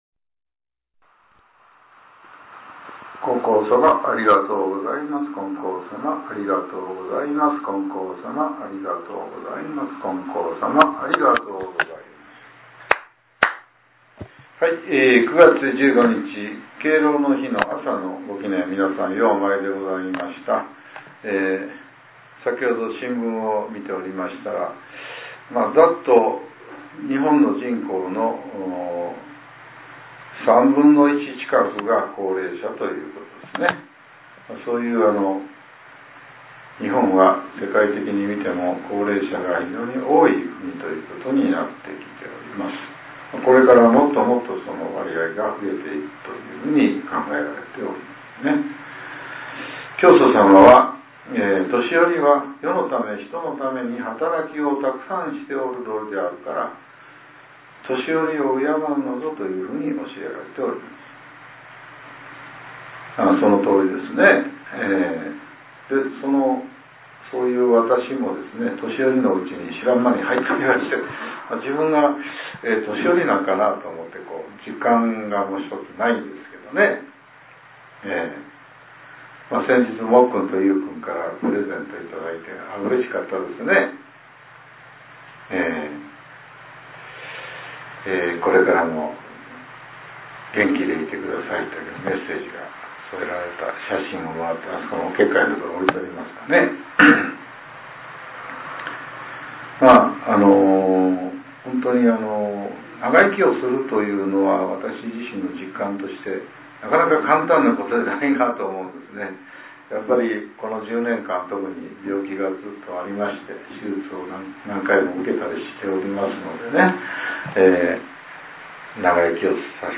令和７年９月１５日（朝）のお話が、音声ブログとして更新させれています。